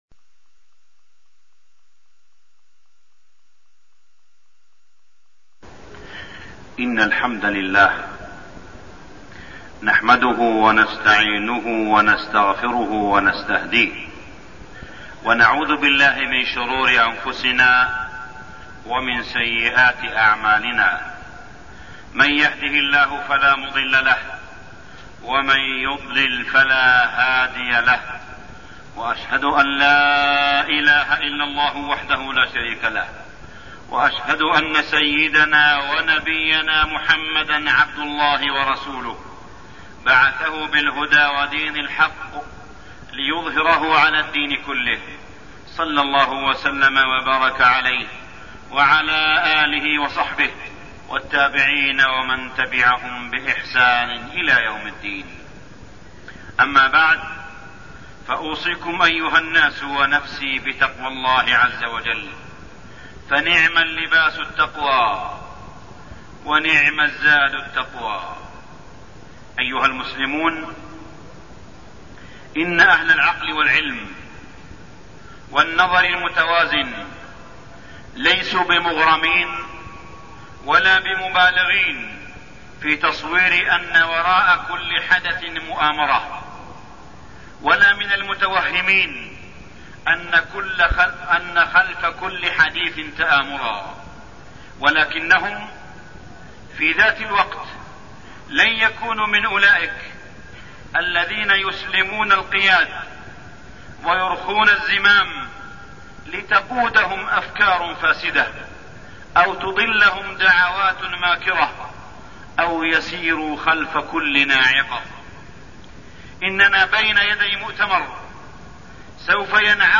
تاريخ النشر ٢٩ ربيع الأول ١٤١٦ هـ المكان: المسجد الحرام الشيخ: معالي الشيخ أ.د. صالح بن عبدالله بن حميد معالي الشيخ أ.د. صالح بن عبدالله بن حميد مؤتمر المرأة والأسرة